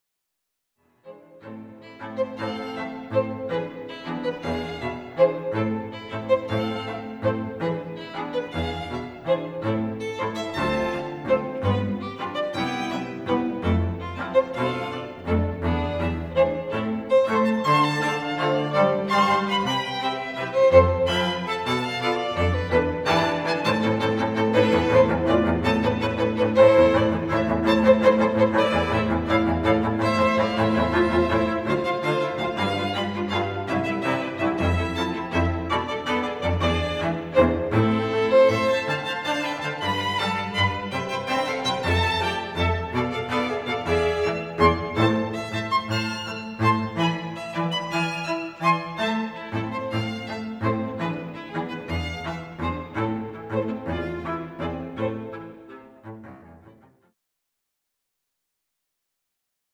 a dreamscape of three Dances for String Quartet.
A rustic Paso Doble and Variations in 5/8 and 3/4 time.